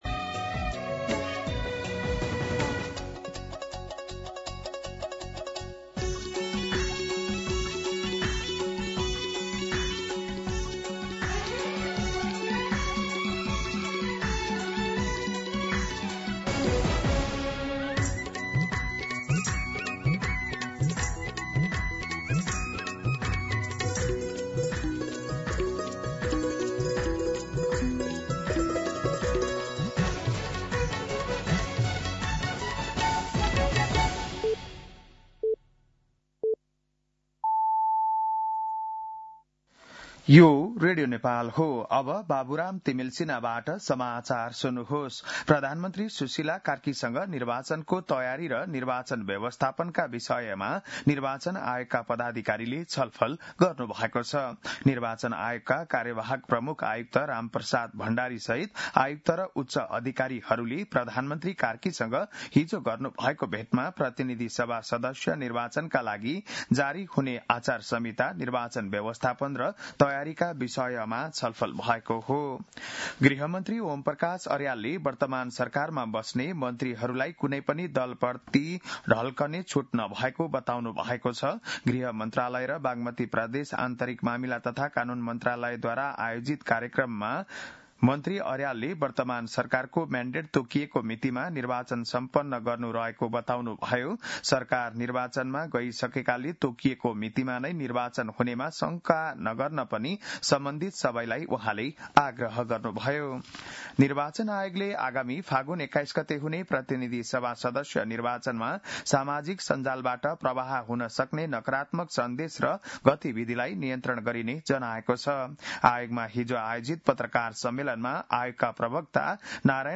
बिहान ११ बजेको नेपाली समाचार : २६ पुष , २०८२
11-am-Nepali-News-3.mp3